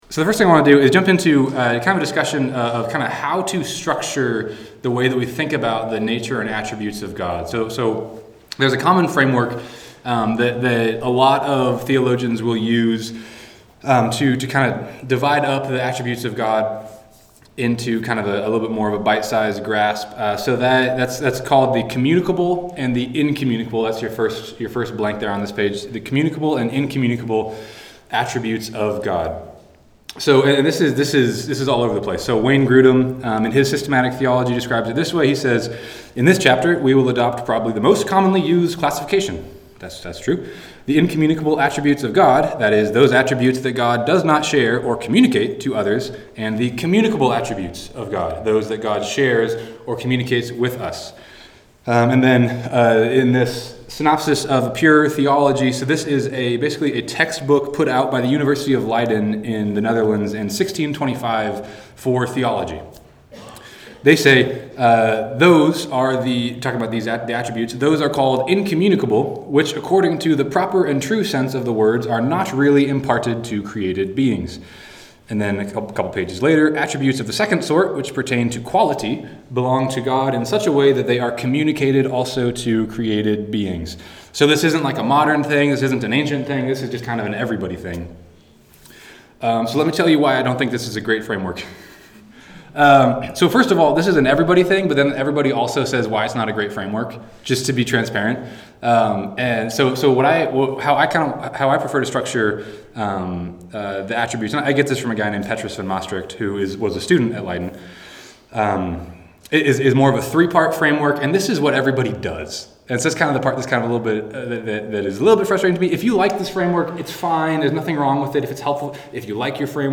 One Day Seminar